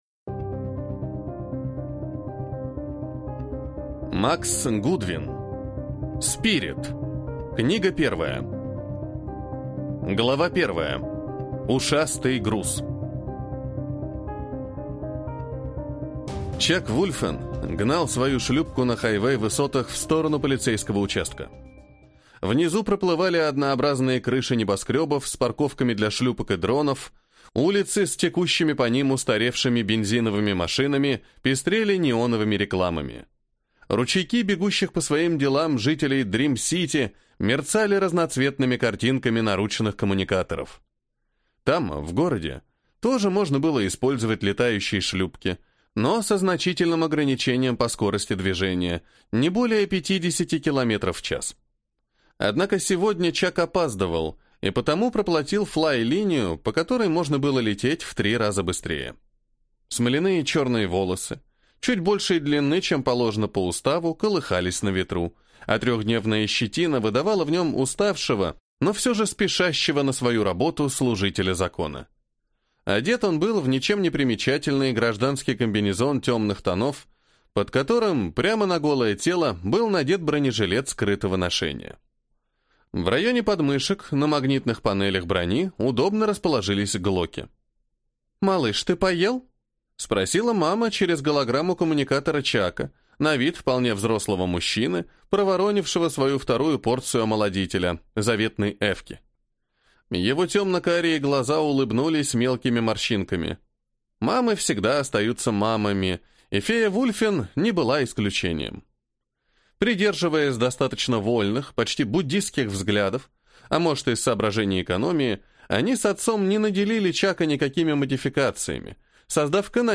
Аудиокнига СПИРИТ. Книга 1 | Библиотека аудиокниг